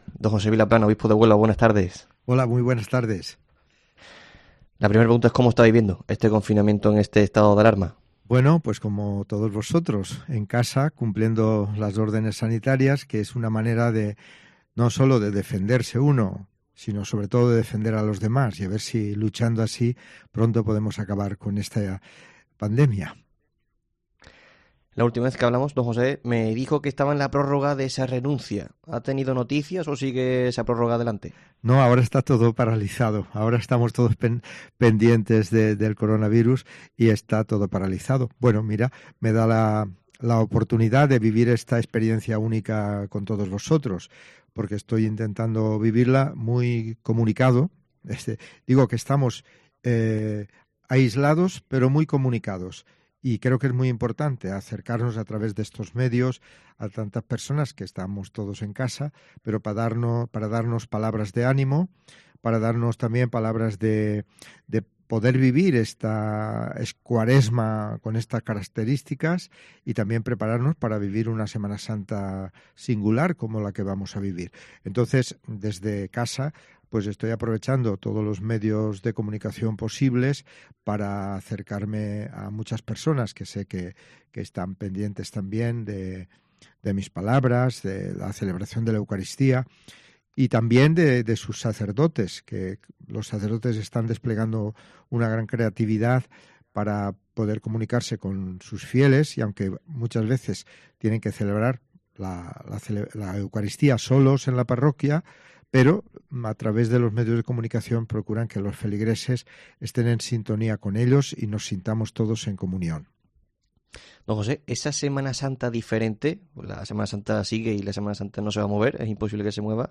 COPE entrevista al obispo de Huelva, José Vilaplana